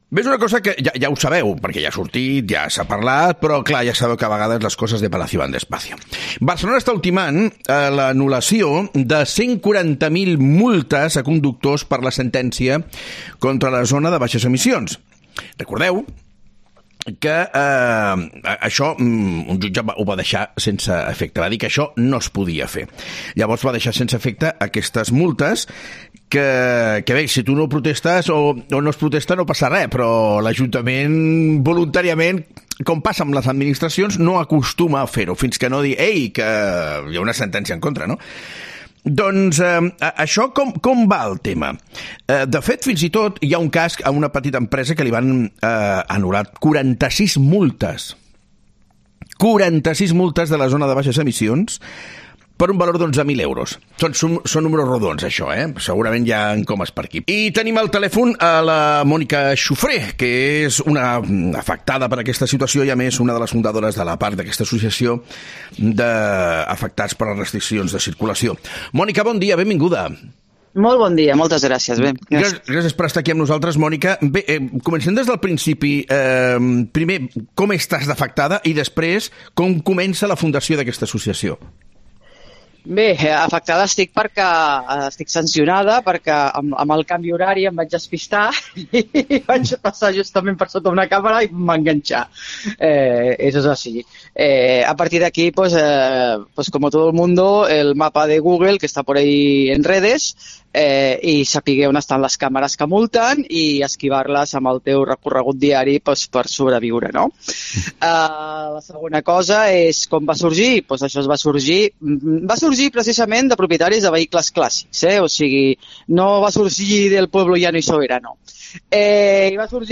En una entrevista con una de las afectadas